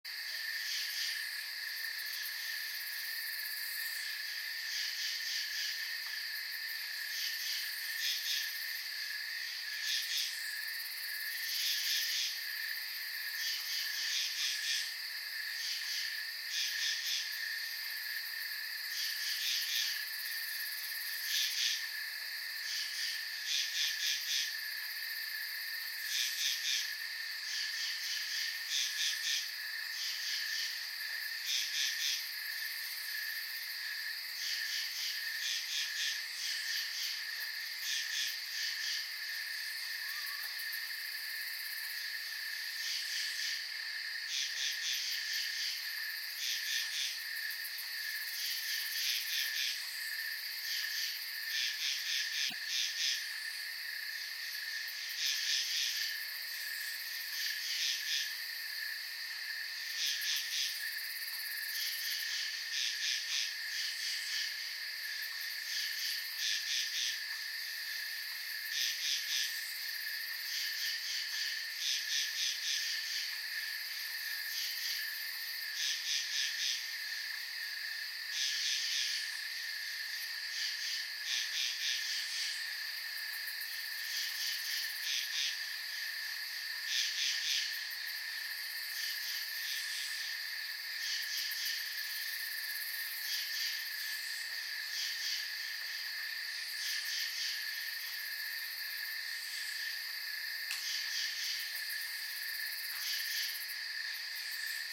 پکیج افکت صوتی طبیعت در شب
پکیج افکت صوتی طبیعت در شب : در این پست کتابخانه افکت های صوتی شامل 696 نوع مختلف طبیعت در شب رو براتون آماده دانلود کرده ایم ، این کتابخانه مجموعه ای از محیط های ضبط شده در طول شب در مکان های مختلف رو برای شما آماده کرده است ، با سافت ساز همراه باشید.
Blastwave.FX.Nature.by.NIght.mp3